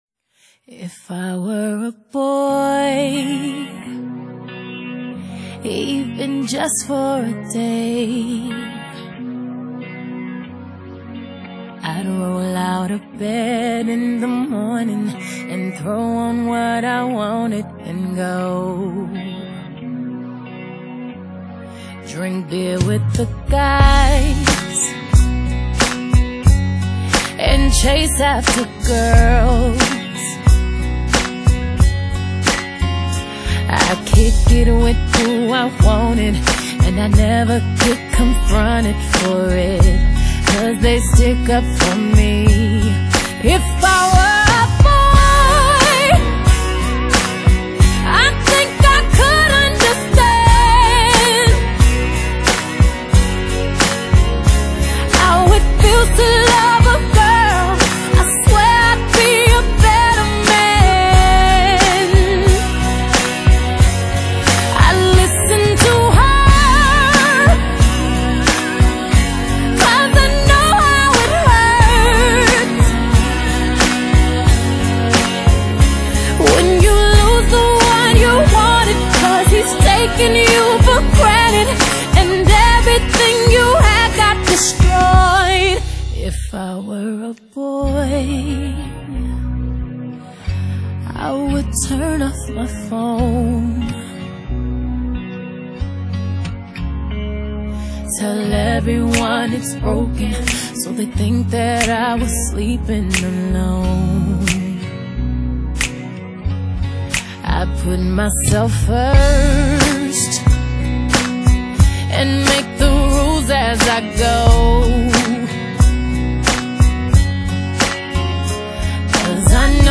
昨聽了一下 這三張選曲都是一些西洋抒情歌曲 絕對值得收藏 推薦給大家聆賞